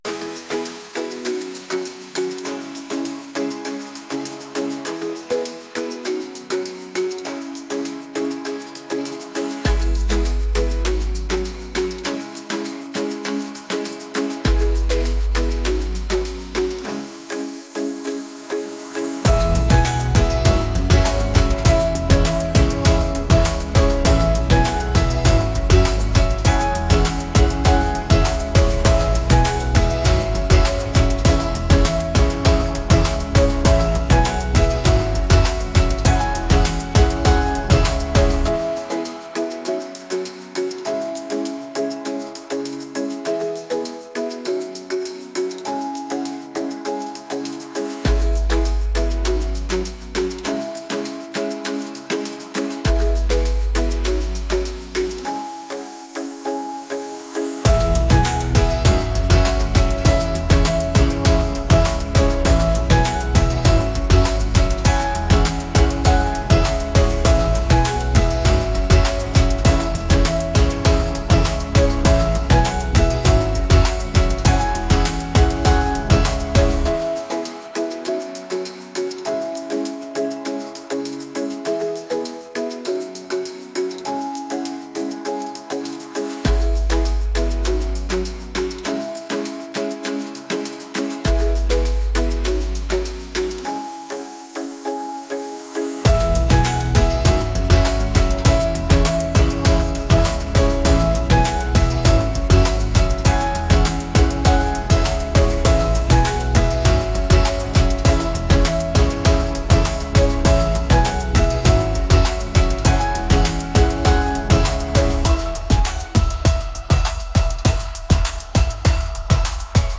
catchy | pop